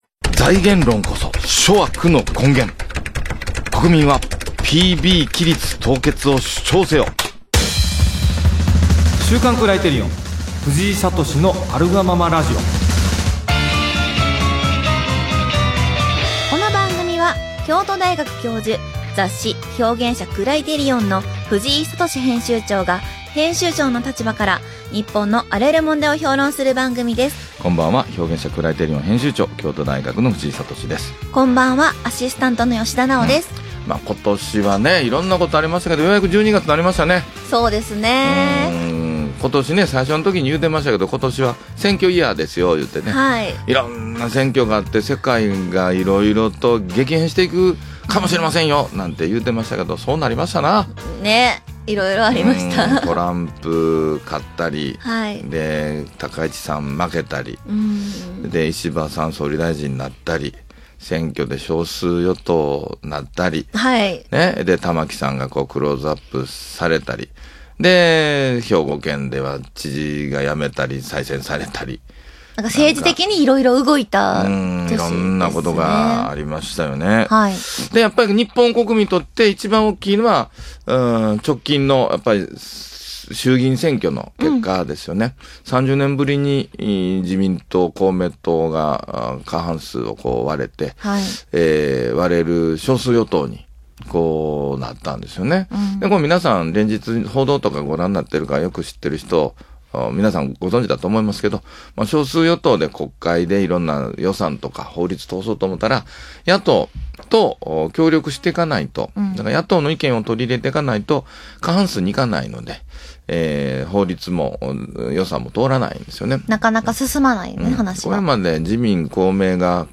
【ラジオ】「財源論」こそ諸悪の根源．国民はPB規律凍結を主張せよ！